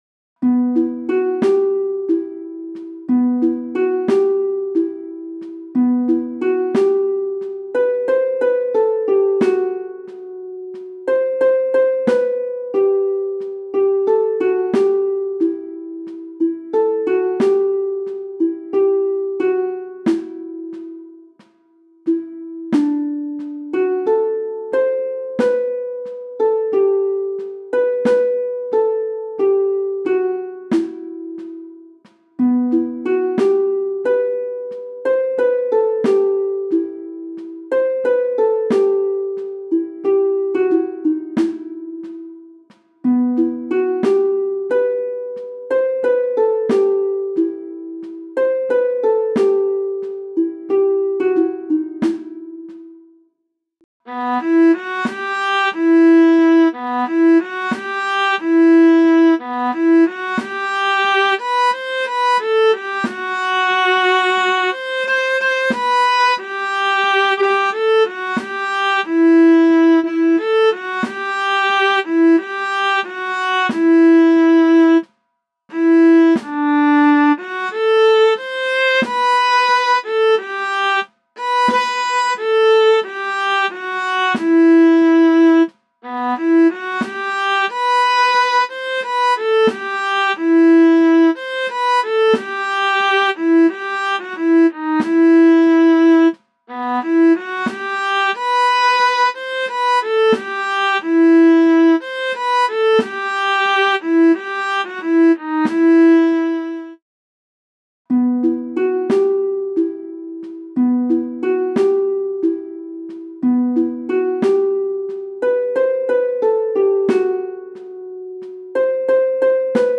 MIDI von 2017 [10.598 KB] - mp3